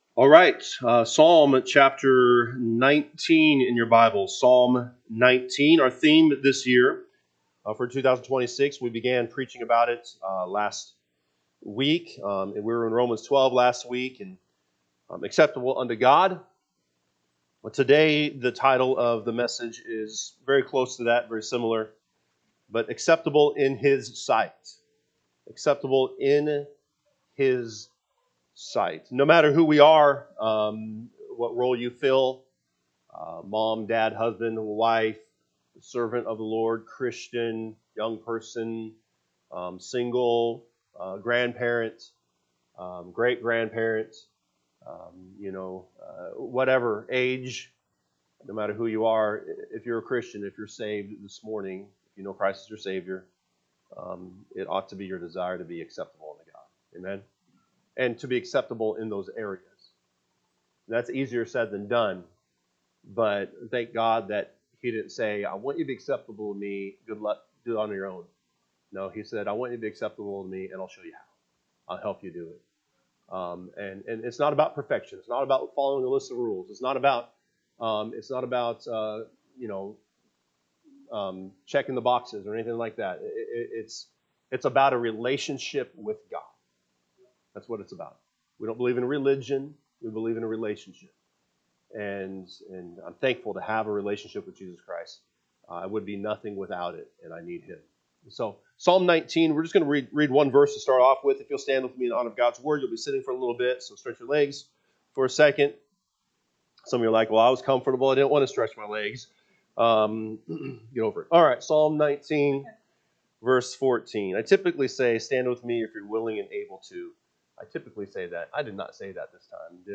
Sunday AM Message